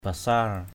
/ba-sa:r/ (d.) ý muốn, ý thích. juai patuei basar anâk lo juai!
basar.mp3